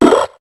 Cri d'Obalie dans Pokémon HOME.